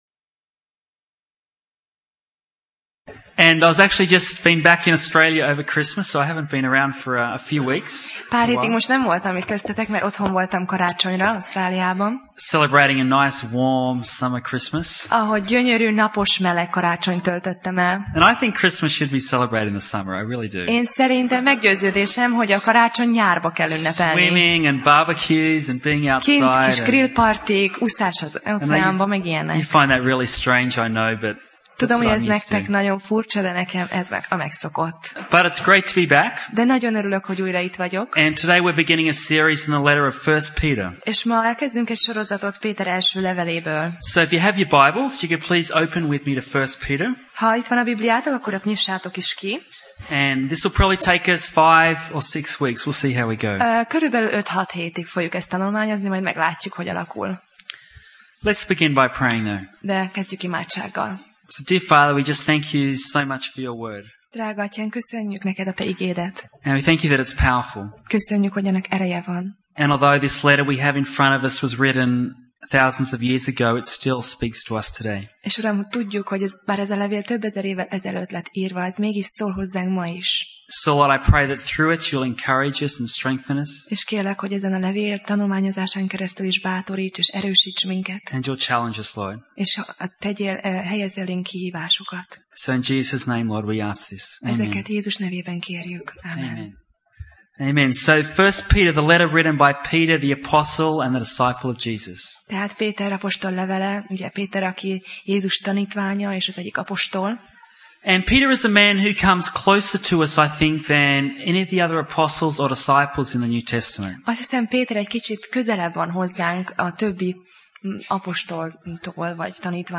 1Péter Passage: 1Péter (1 Peter) 1:1–9 Alkalom: Vasárnap Reggel